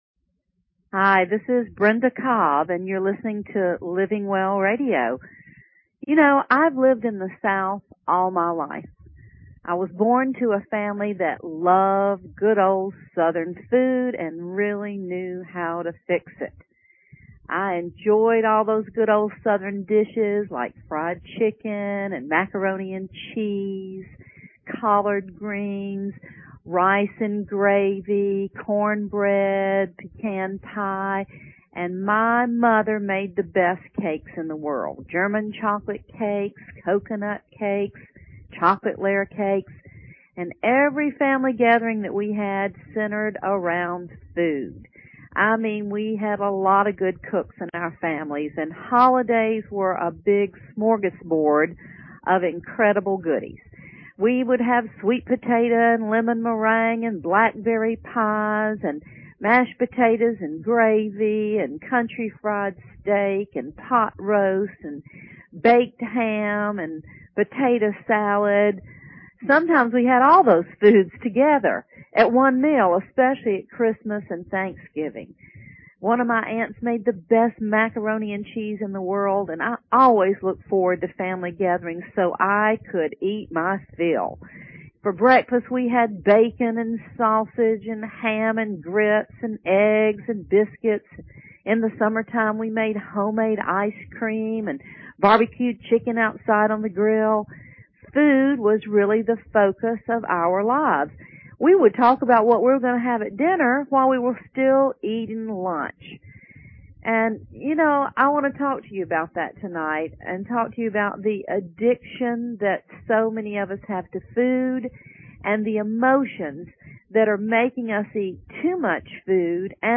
Talk Show Episode, Audio Podcast, Living_Well and Courtesy of BBS Radio on , show guests , about , categorized as